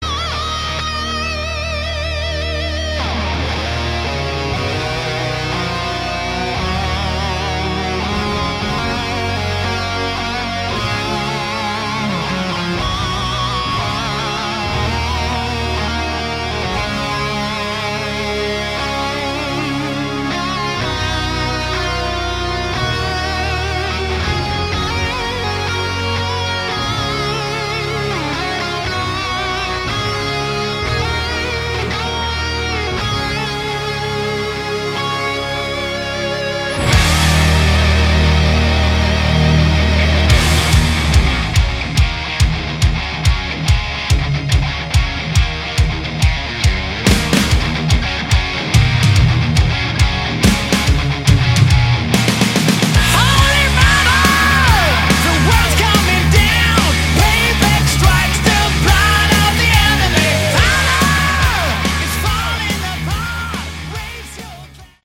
Category: Hard Rock
lead vocals, guitar
lead guitars
drums
bass